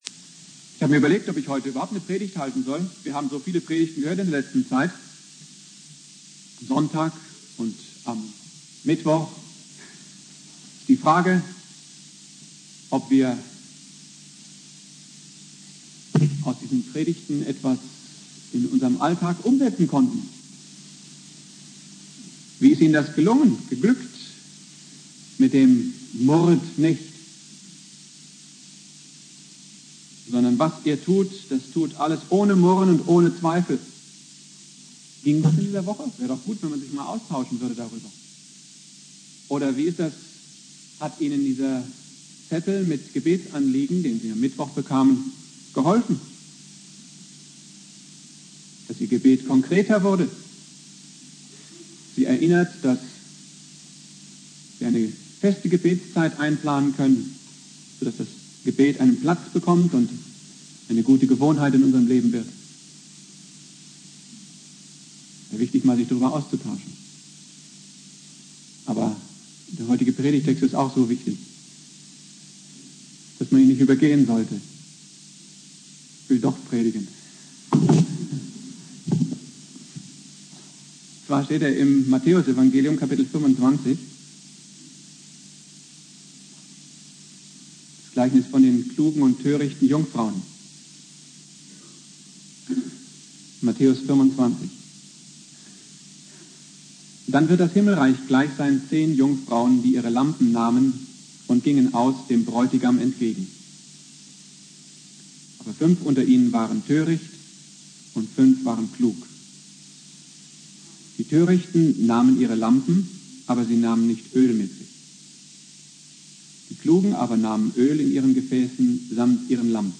Ewigkeitssonntag Prediger